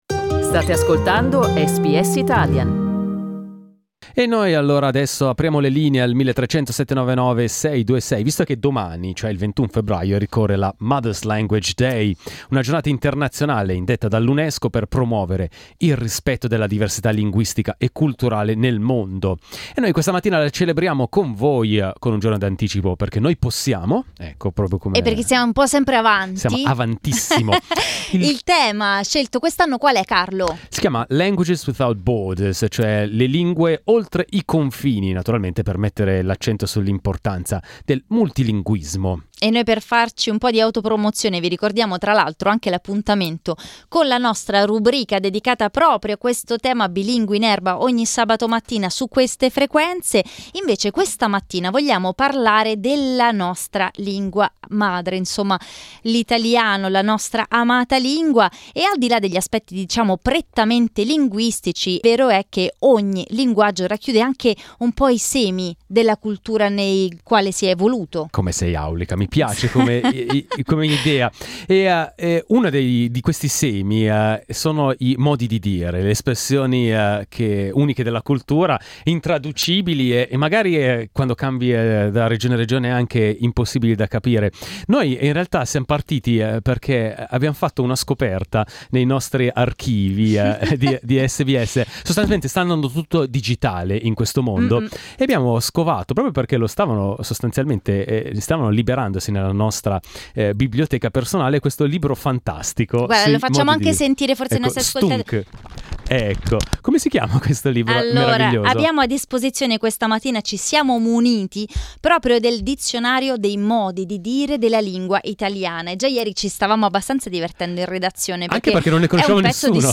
negli studi di SBS Italian